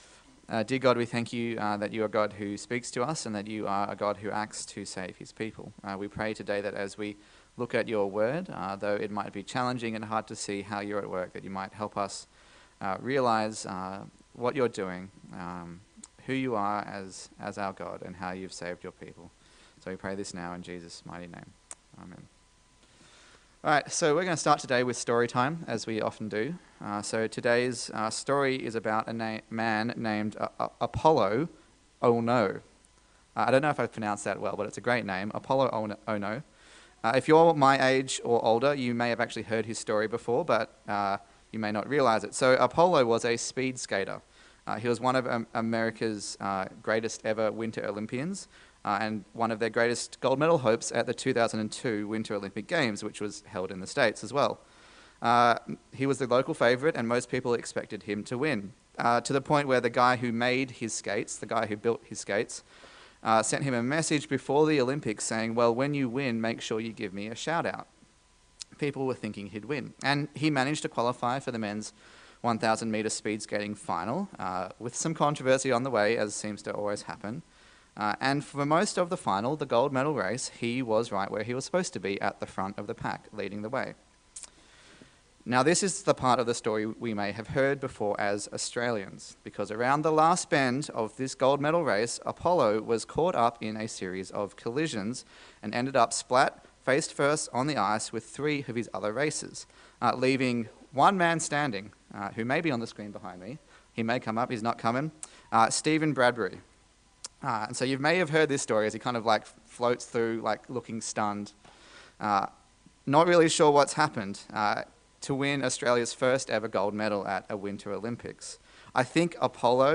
Esther Passage: Esther 5:9-7:10 Service Type: Sunday Service